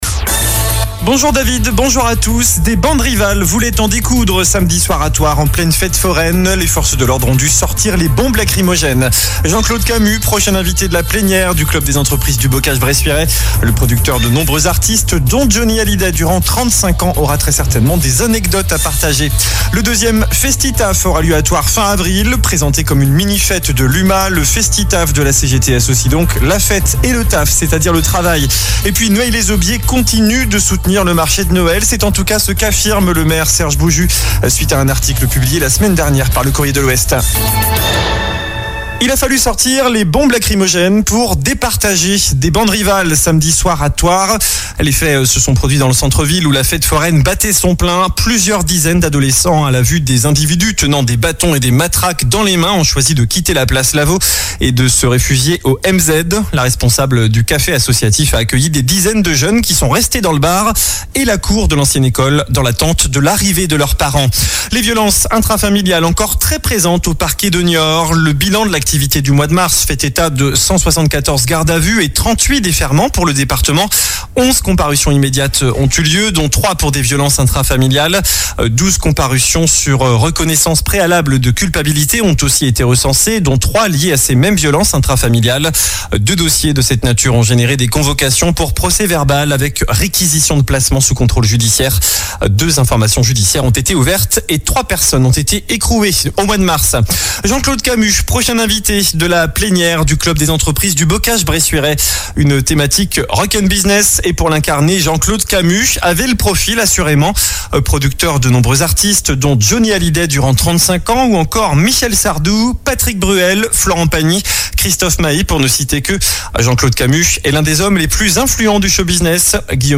Journal du lundi 8 avril (midi)